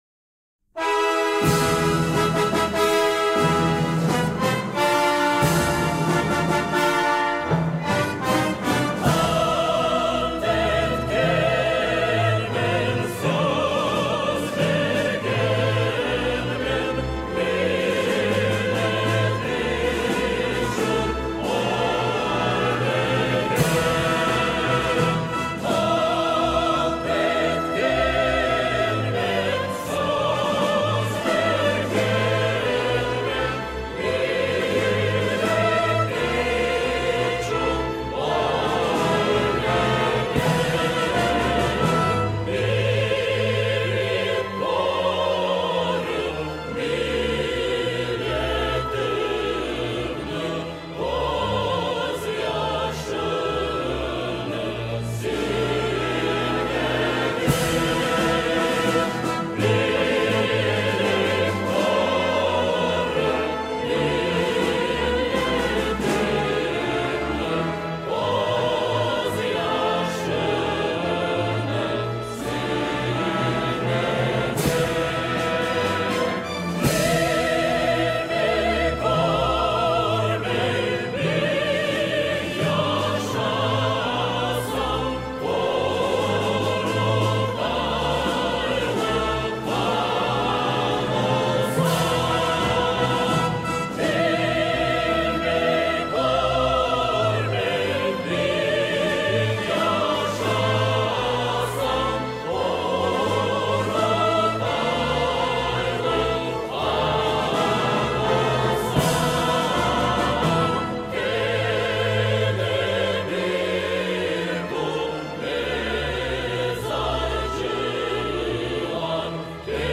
• Качество: высокое
Мелодия передает глубину традиций и любовь к родной земле.
со словами